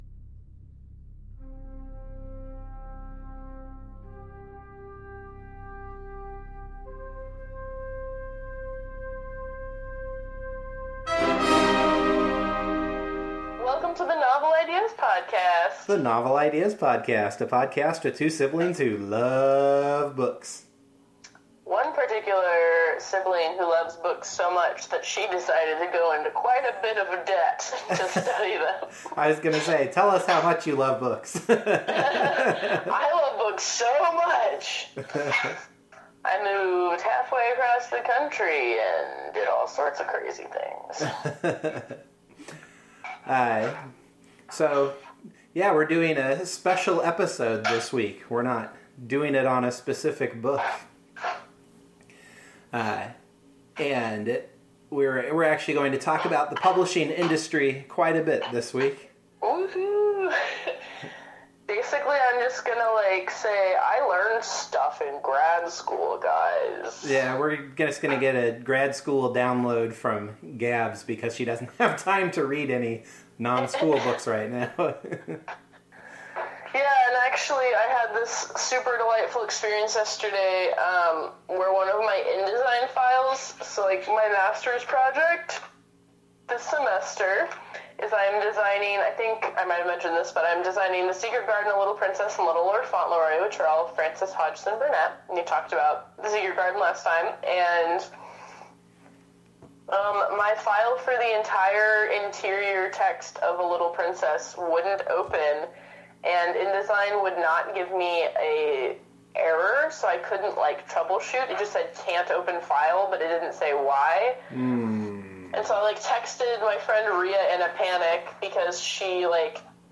The music bump is “Also Sprach Zarathustra” by Richard Strauss.